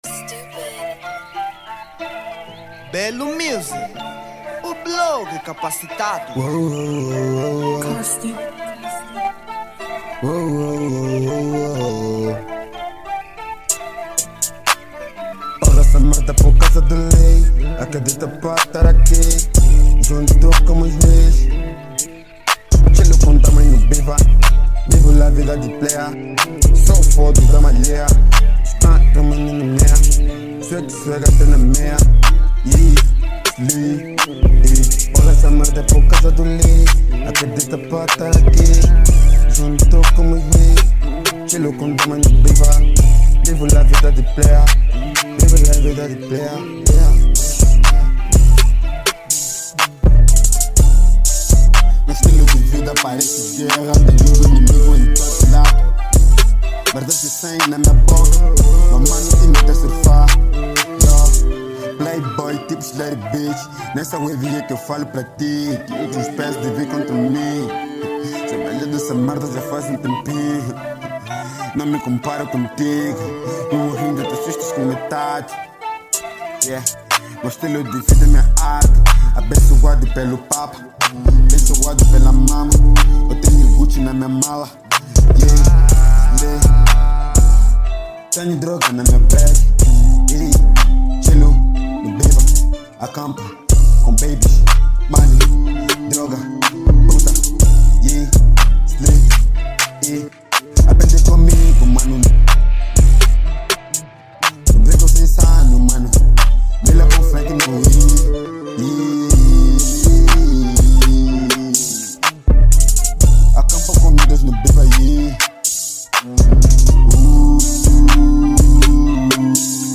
Género: Trap